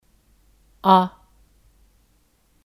啊 a
Agar kalimat terdengar lebih ramah dan alami, huruf ini tidak memiliki nada sehingga bisa diucapkan secara spontan sesuai dengan emosi di saat menggunakannya.